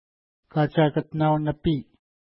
ID: 422 Longitude: -62.4868 Latitude: 54.0004 Pronunciation: ka:tʃa:kətna:w-nəpi: Translation: Mountain with a Steep Face on One Side Lake Feature: lake Explanation: Named in reference to nearby mountain Katshakatinat (no 425).